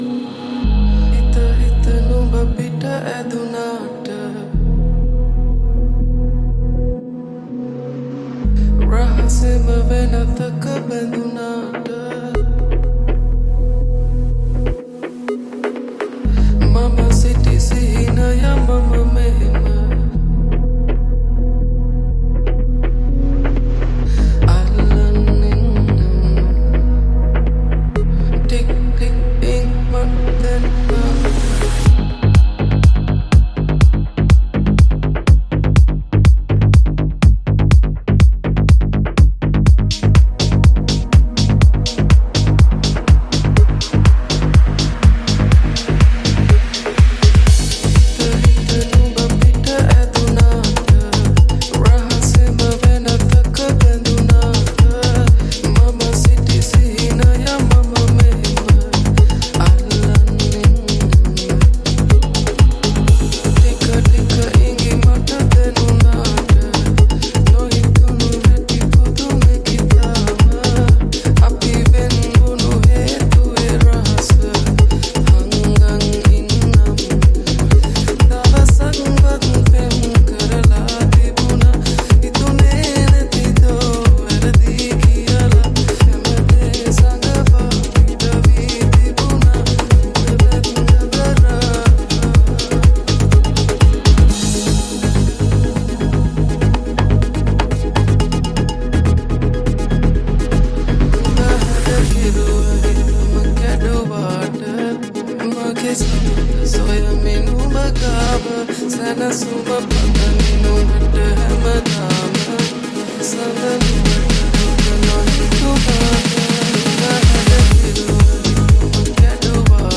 x Sinhala Progressive House
Vocals